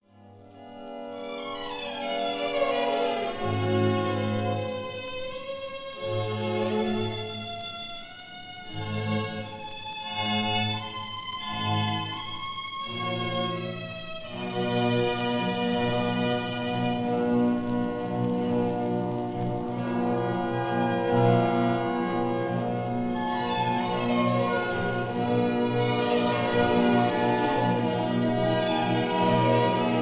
Adagio - Allegro
recorded in 1928